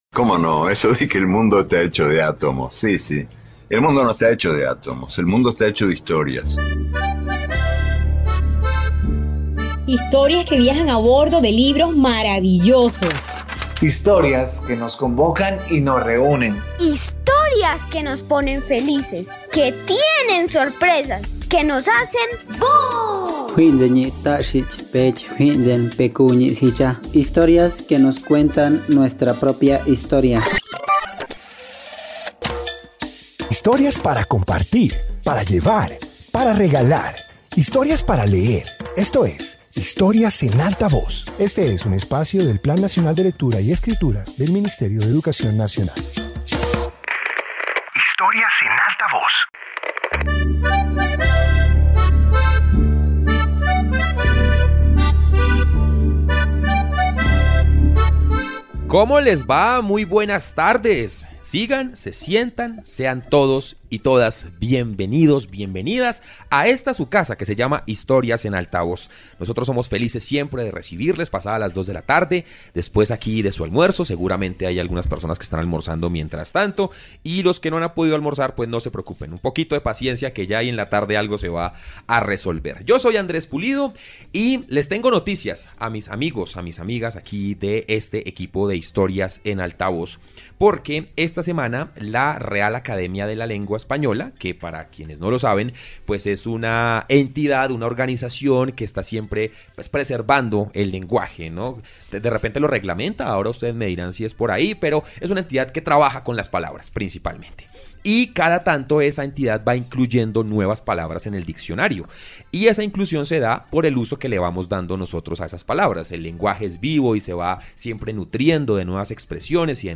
Introducción Este episodio de radio explora términos poco comunes y juegos del lenguaje. Presenta relatos que muestran la creatividad de las palabras y los múltiples significados que pueden adquirir en distintos contextos.